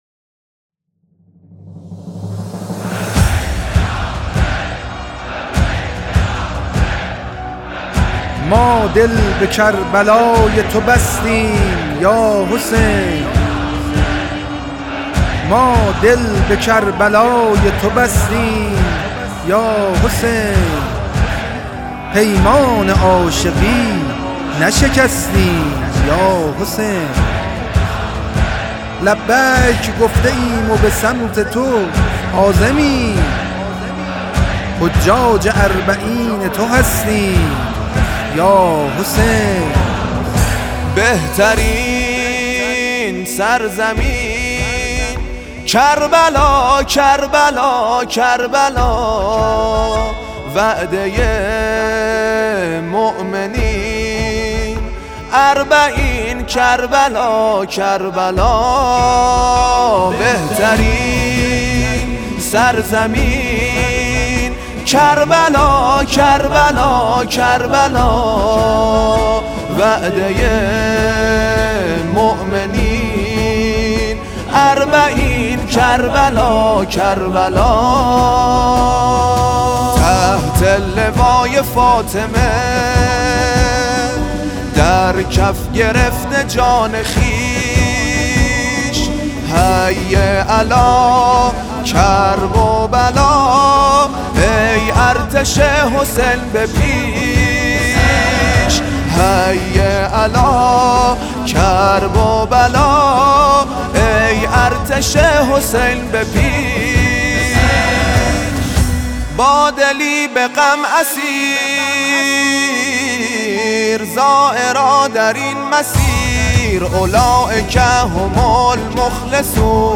شاعر و مداح اهل بیت (ع)